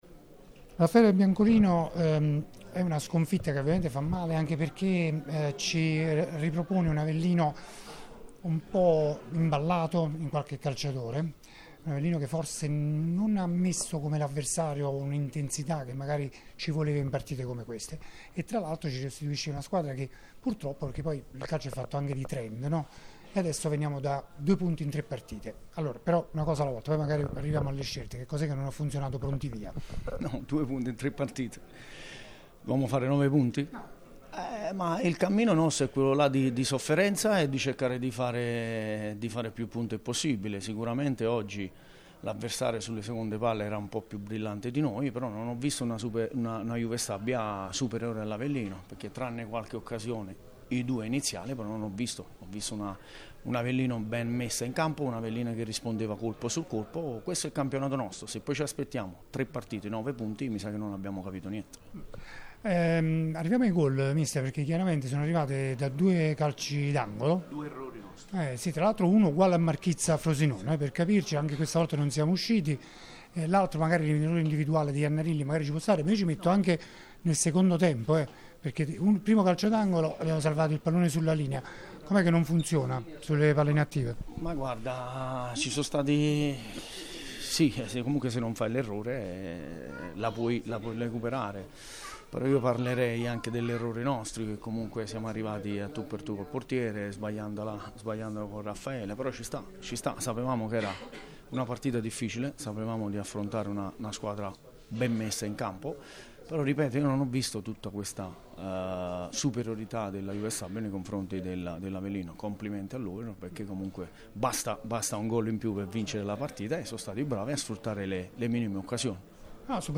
Nel post-gara il tecnico dei lupi ai microfoni di Radio Punto Nuovo.
LE DICHIARAZIONI DI RAFFAELE BIANCOLINO